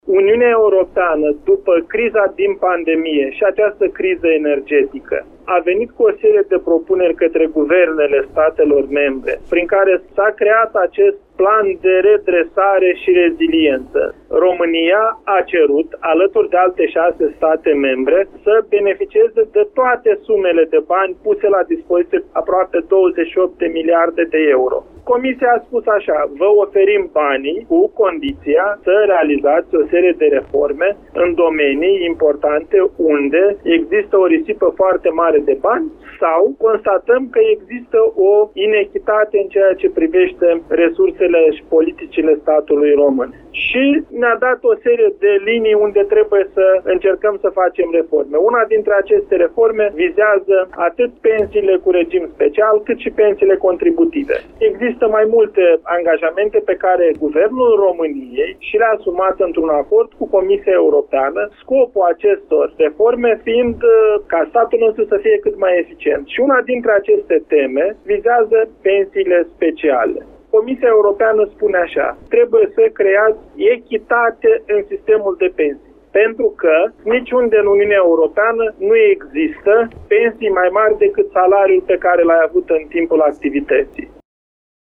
Desființarea pensiilor speciale (care nu sunt bazate pe contributivitate) este o recomandare a Comisiei Europene acceptată de Guvernul României, care trebuie neapărat s-o pună în practică, altfel vom pierde sume importante din PNRR. Detalii de la Bruxelles ne-a oferit europarlamentarul Eugen Tomac: